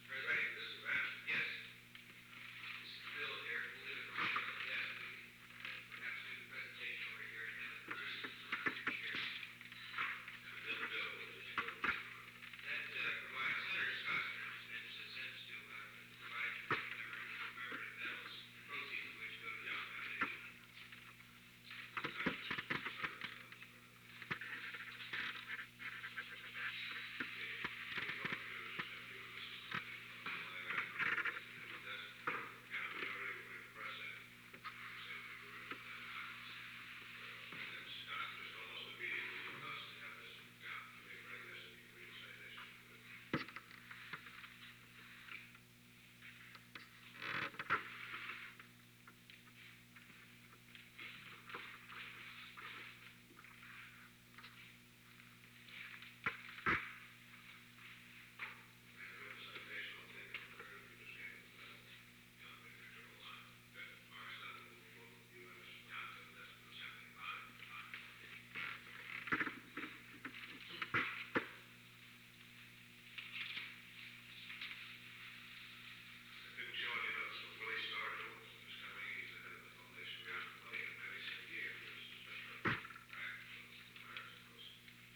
Secret White House Tapes
Conversation No. 917-22
Location: Oval Office
The President met with unknown men.